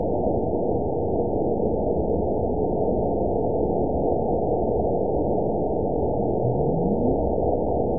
event 920190 date 03/04/24 time 22:36:03 GMT (1 year, 2 months ago) score 8.73 location TSS-AB02 detected by nrw target species NRW annotations +NRW Spectrogram: Frequency (kHz) vs. Time (s) audio not available .wav